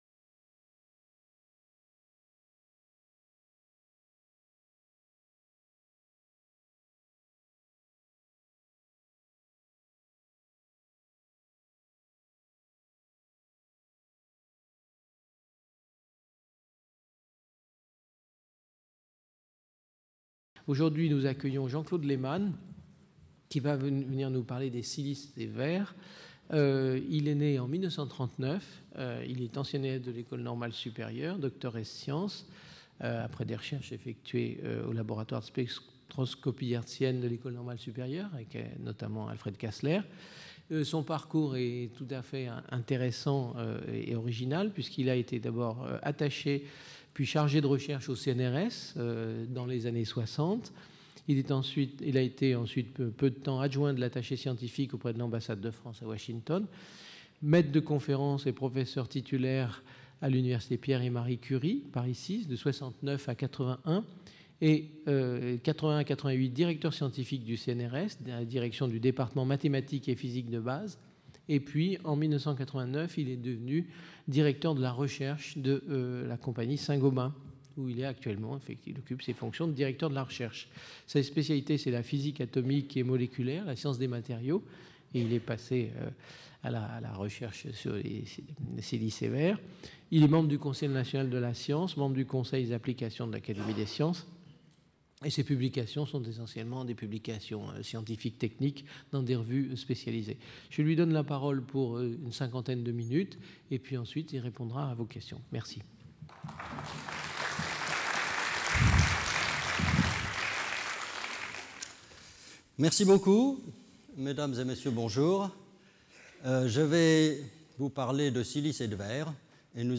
Cette conférence commencera par présenter une analyse de la notion de verre : si les gaz et liquides sont faciles à décrire, les solides peuvent prendre des structures très variées au niveau de l'arrangement des atomes qui les composent.